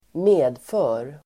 Uttal: [²m'e:dfö:r]